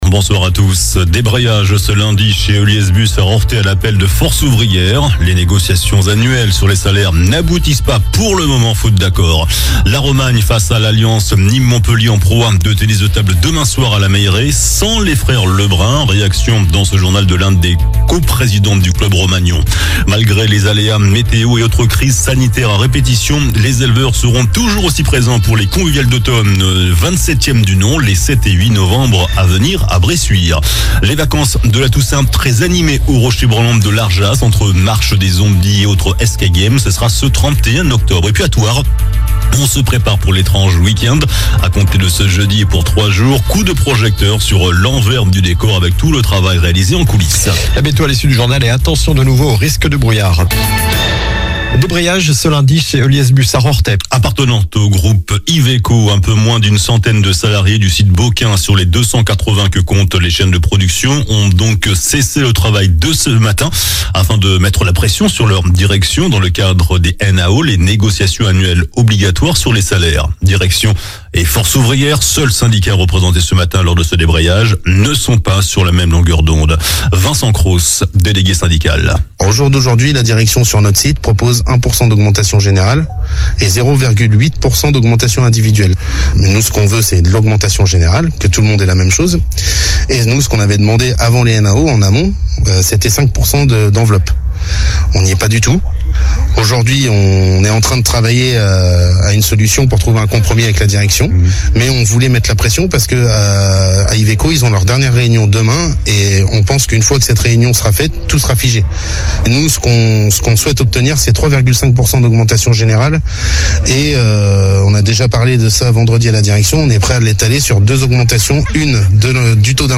Journal du lundi 28 octobre (soir)